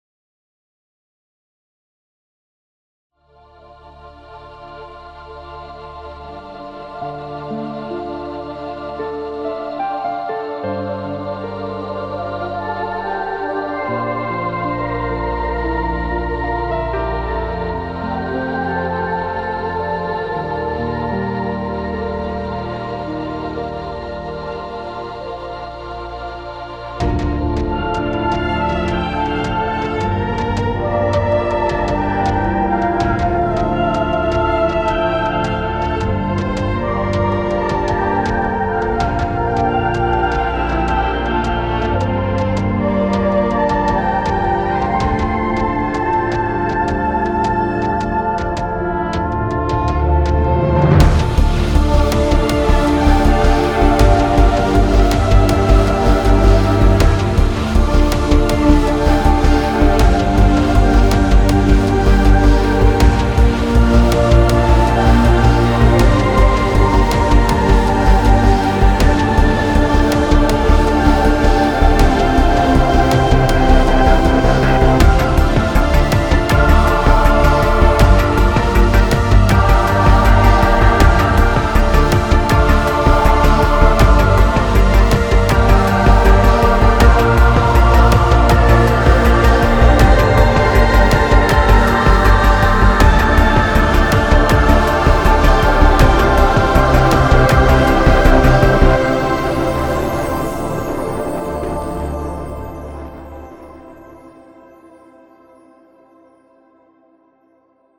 Fallen Angels is a cinematic Sci-Fi Pad Vocals soundset for Reveal Sound Spire, designed to deliver ethereal textures, otherworldly atmospheres, and haunting vocal-like pads that elevate your productions to new emotional depths.
This pack features 100 expertly crafted presets, ranging from lush ambient vocal pads and evolving spectral textures to cosmic vocal swells and cinematic atmospheric layers.
• Dark, cinematic aesthetic: vocals that feel futuristic, enigmatic, and emotionally charged.
Fallen Angels delivers haunting vocal pads and cinematic atmospheres with futuristic character — perfect for adding emotional motion and immersive depth to your tracks.
• * The video and audio demos contain presets played from Fallen Angels sound bank, every single sound is created from scratch with Spire.